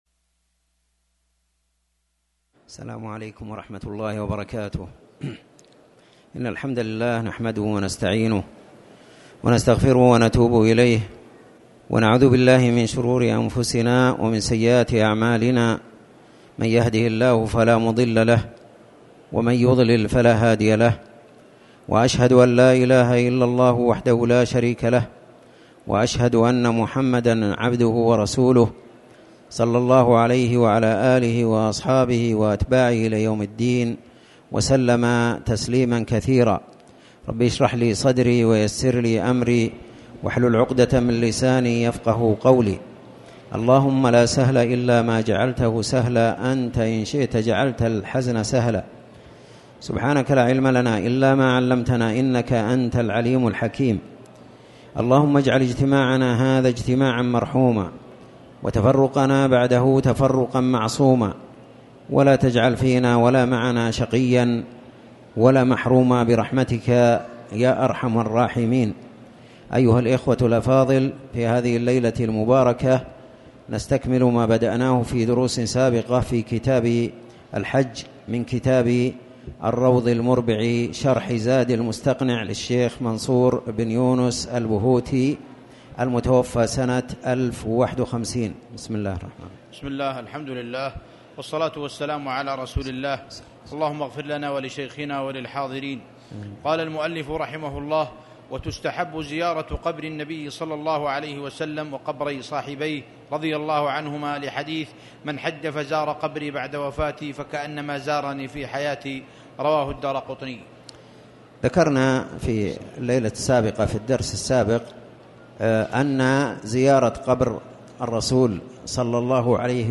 تاريخ النشر ٢٥ صفر ١٤٣٩ هـ المكان: المسجد الحرام الشيخ